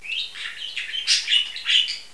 Wissenswertes ueber Wellensittiche :: Wellensittich Audio files
Hier jetzt einige "Songs" von unseren Sittichen, beim Schlummerstündchen und während des Spielens aufgenommen.
tschirp7.wav